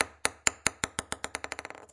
乒乓球 " 乒乓球 6
描述：14号塑料乒乓球从6英寸下降。到一张木桌上。记录到逻辑 16/441
Tag: 离奇 乒乓球 节奏 声音 紧凑